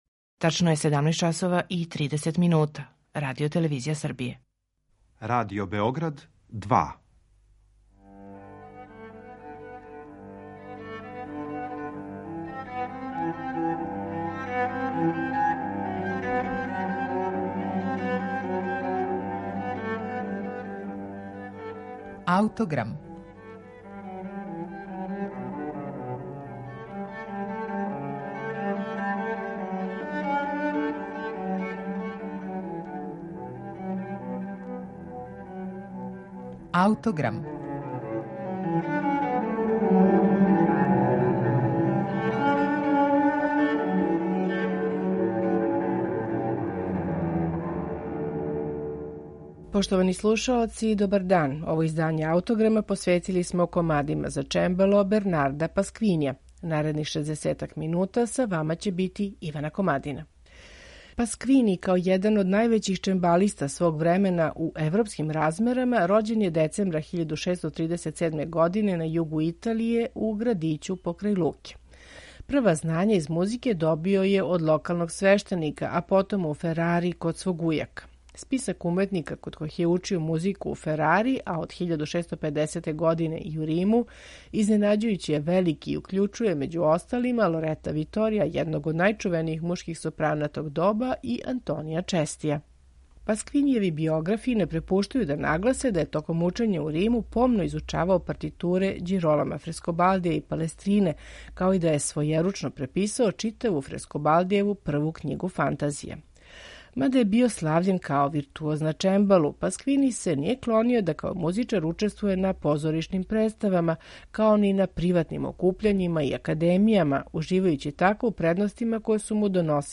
Пасквини: Комади за чембало